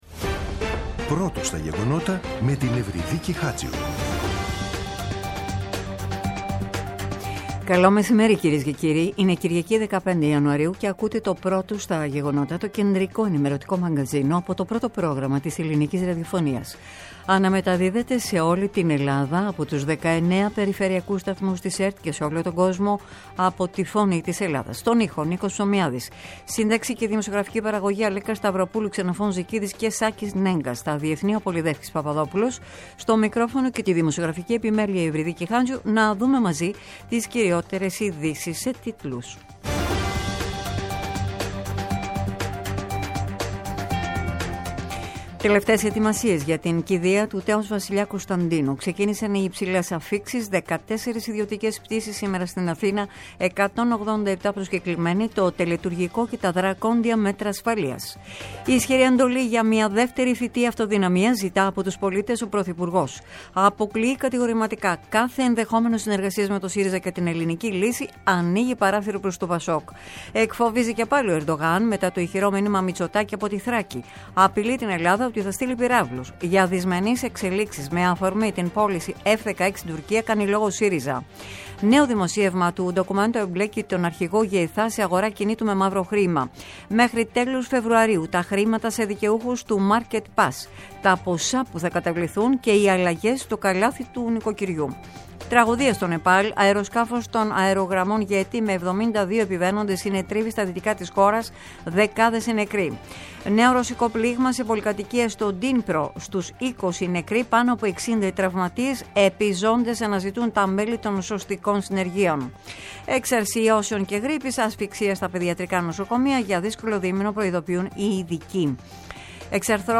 “Πρώτο στα γεγονότα”. Το κεντρικό ενημερωτικό μαγκαζίνο του Α΄ Προγράμματος στις 14.00. Με το μεγαλύτερο δίκτυο ανταποκριτών σε όλη τη χώρα, αναλυτικά ρεπορτάζ και συνεντεύξεις επικαιρότητας.